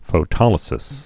(fō-tŏlĭ-sĭs)